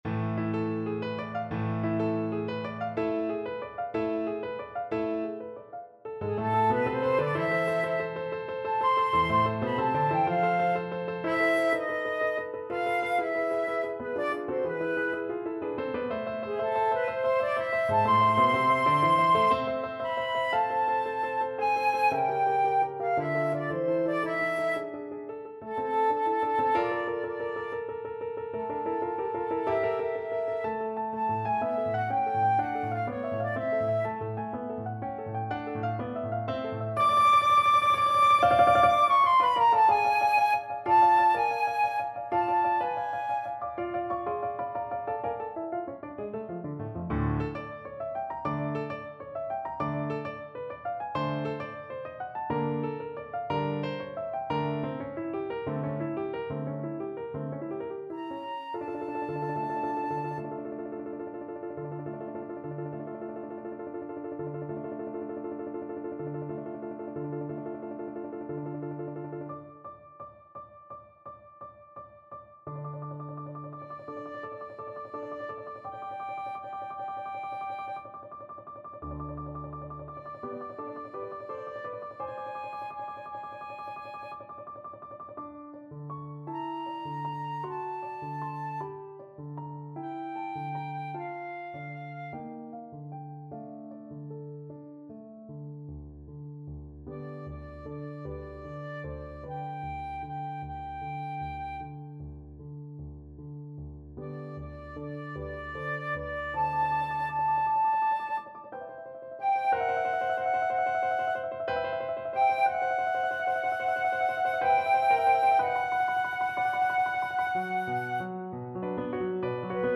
9/8 (View more 9/8 Music)
Schnell und wild = 185
Classical (View more Classical Flute Music)